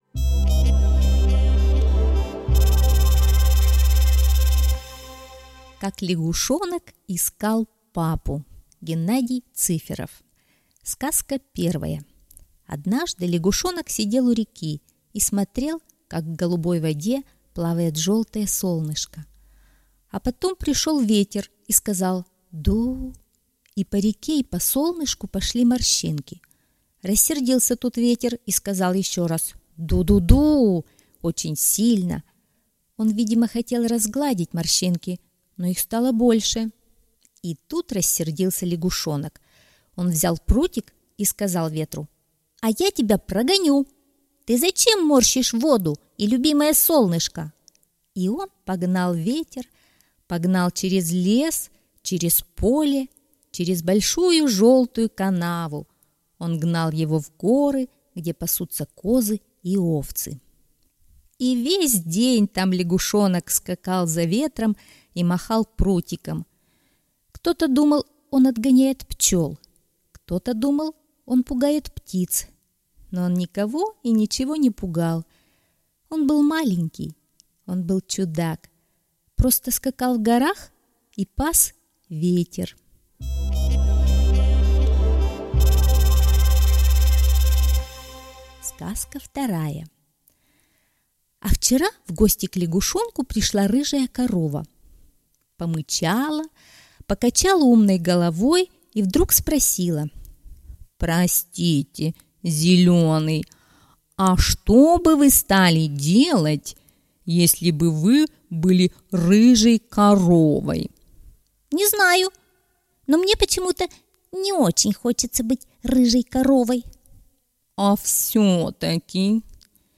Как лягушонок папу искал - аудиосказка Цыферова Г.М. Как лягушонок искал папу — серия коротких историй из жизни чудака-лягушонка.